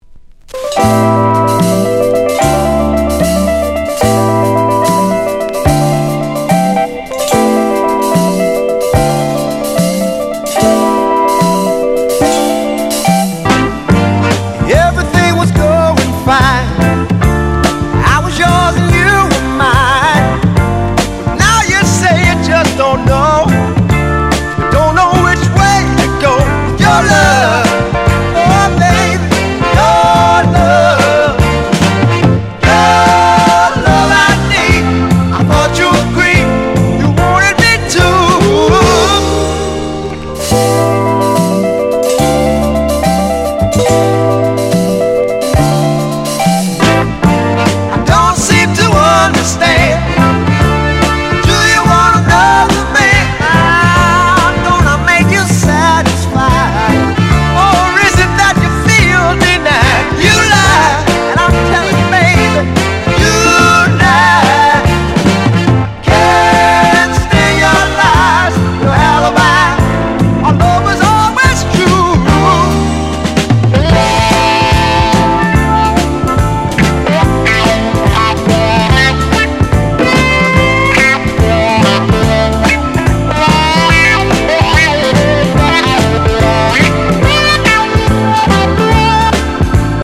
メロウ・シャッフル・ダンサー